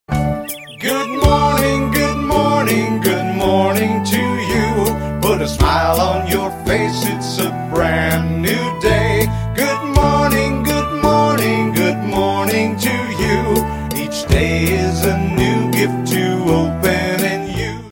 Category: Bird Ringtones